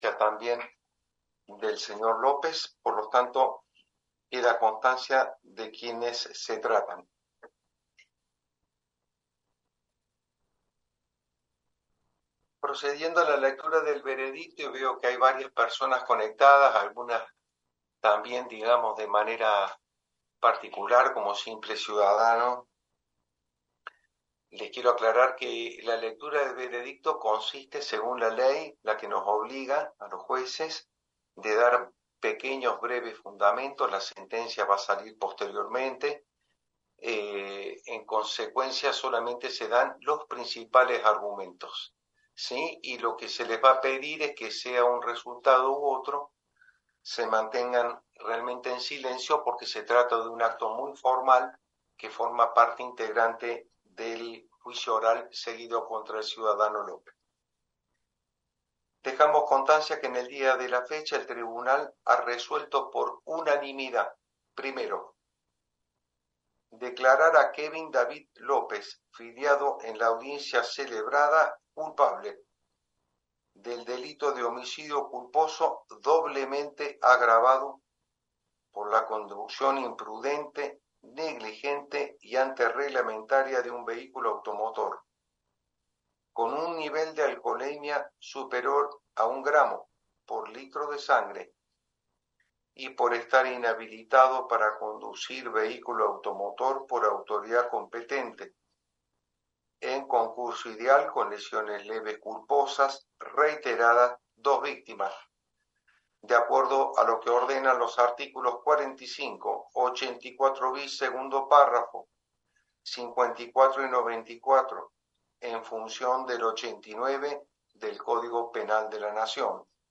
El juez Sánchez Freytes fue el encargado de dar a conocer el veredicto.